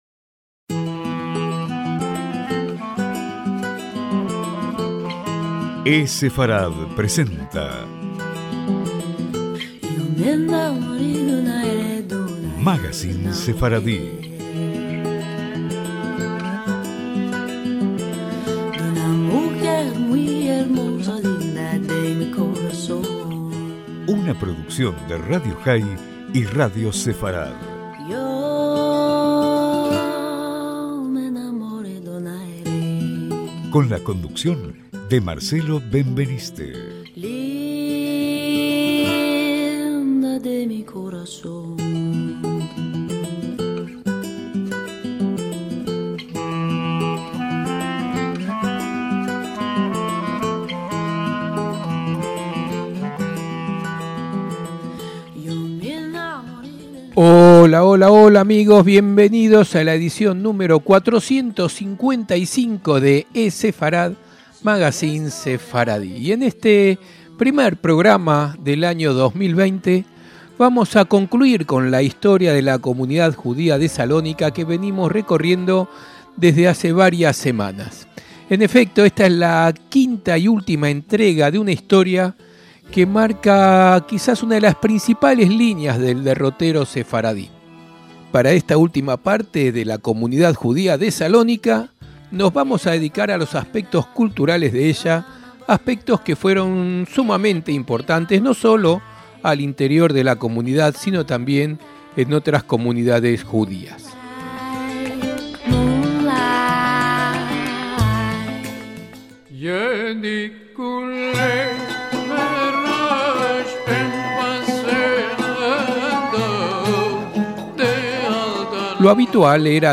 Para esta última parte de la Comunidad Judía de Salónica nos vamos a dedicar a los aspectos culturales de ella, aspectos que fueron sumamente importantes no solo al interior de la comunidad sino también en otras comunidades judías. Incluimos el poema de Jorge Luis Borges "Una llave de Salónica", recitada por el mismo autor.¡Semanada buena!